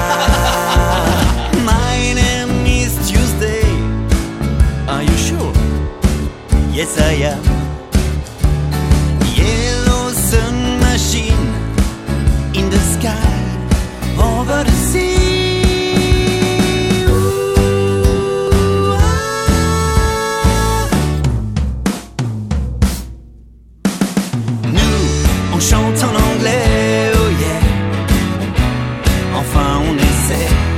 un duo de musiciens-chanteurs pour le jeune public